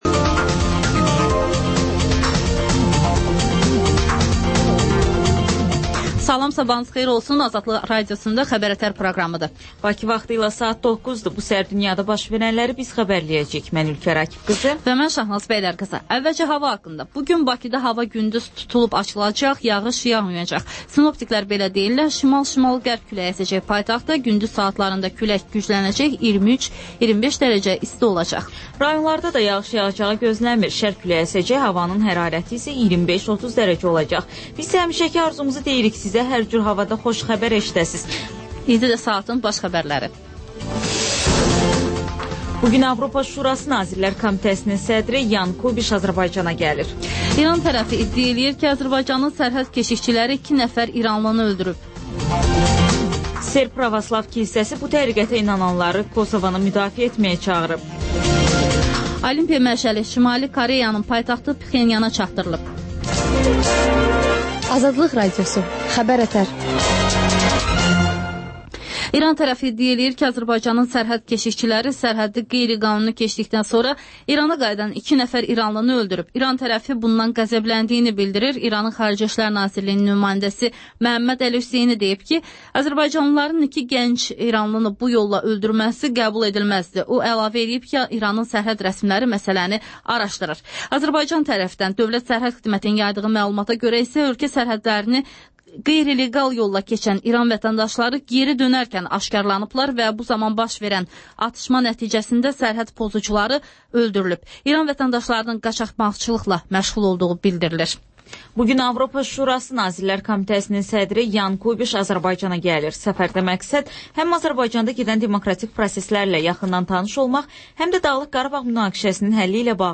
Xəbər-ətər: xəbərlər, müsahibələr, sonra TANINMIŞLAR rubrikası: Ölkənin tanınmış simaları ilə söhbət